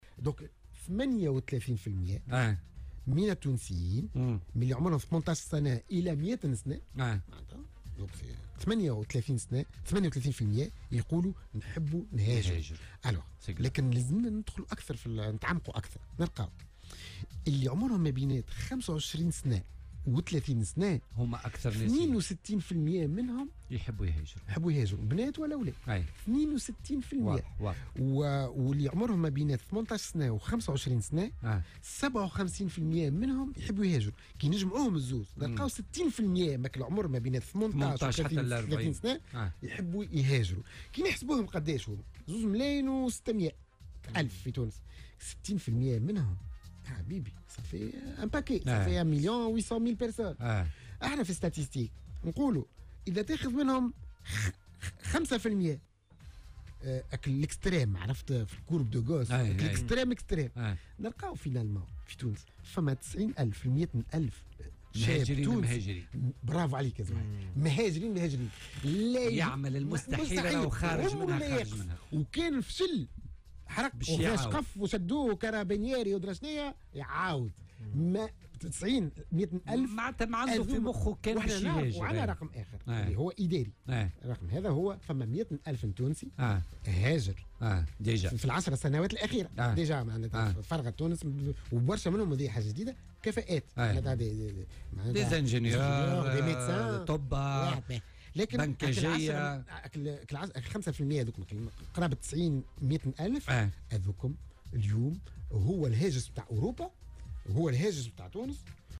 وأضاف في مداخلة له اليوم على "الجوهرة أف أم" أن 62 بالمائة من الذين تتراوح أعمارهم بين 25 و30 سنة يفضلون العيش خارج تونس.